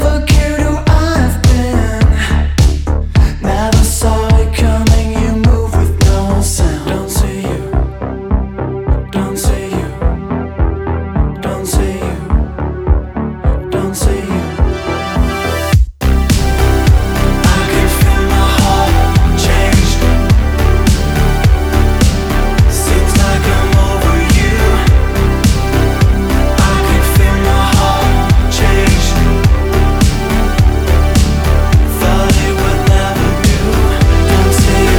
Жанр: Поп / Инди
# Indie Pop